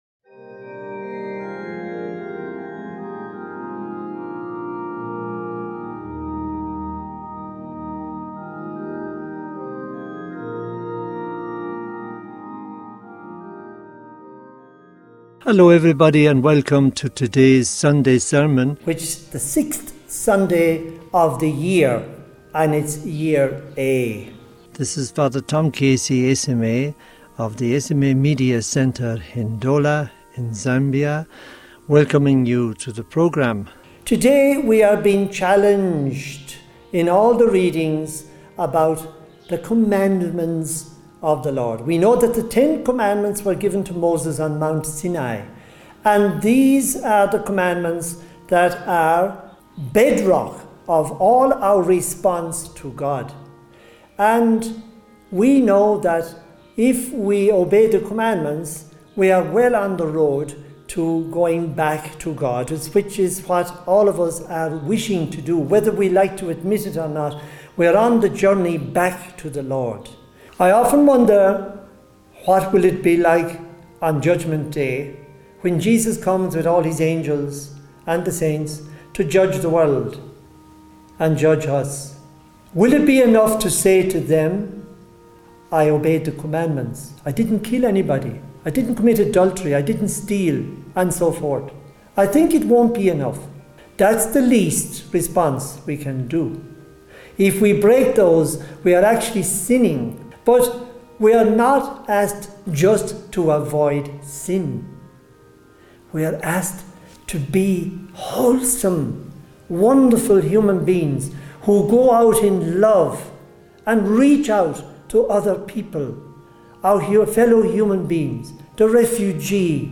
Homily for the 6th Sunday of Ordinary Time 2026